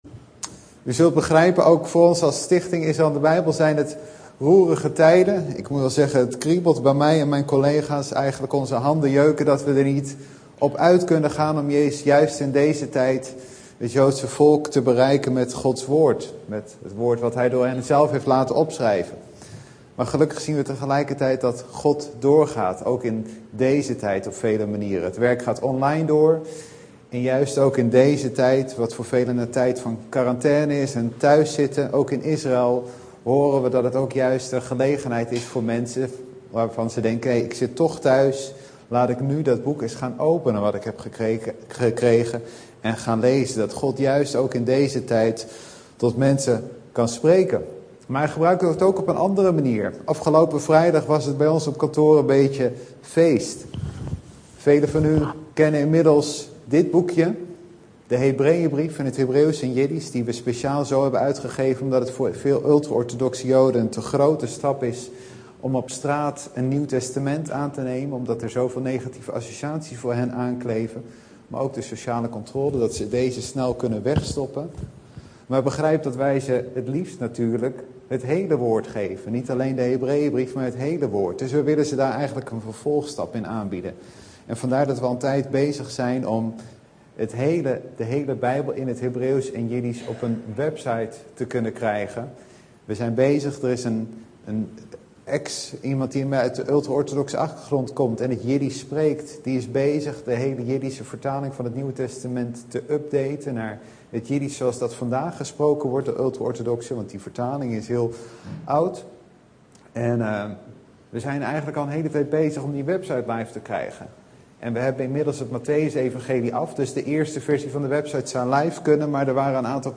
In de preek aangehaalde bijbelteksten (Statenvertaling)1 Samuel 171